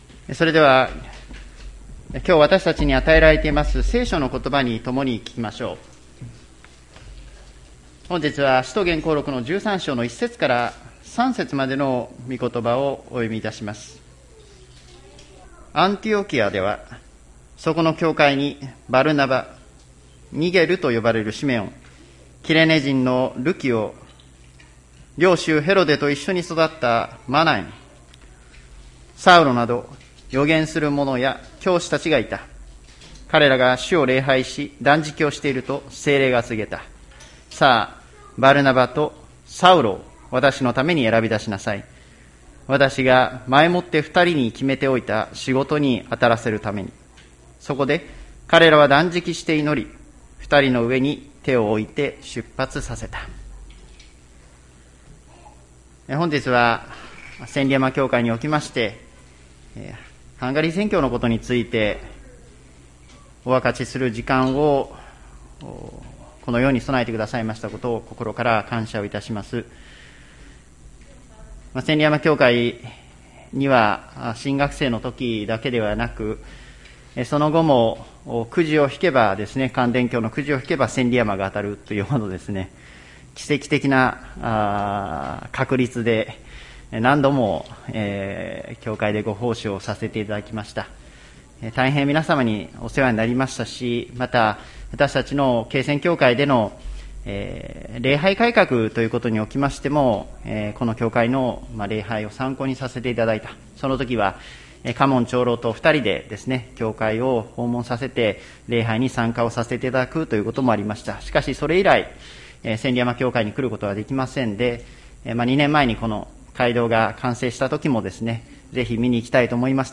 礼拝説教 日曜夕方の礼拝